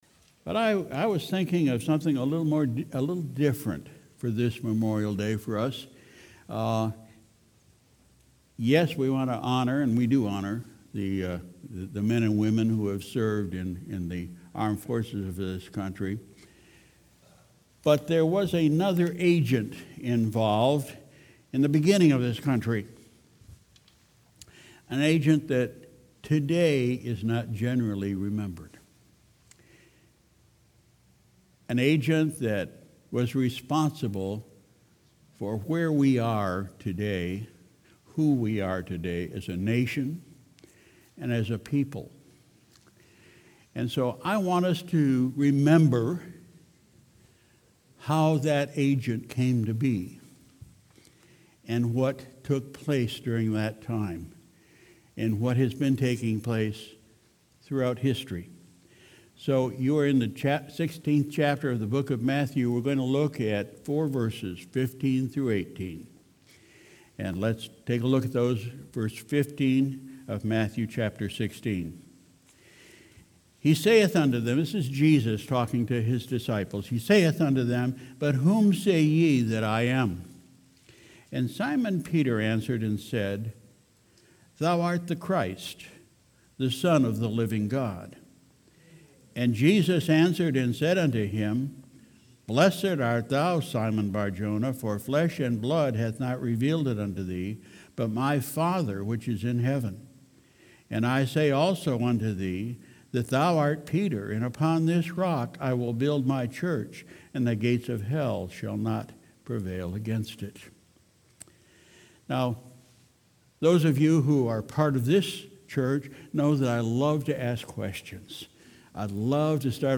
Sunday, May 26, 2019 – Morning Service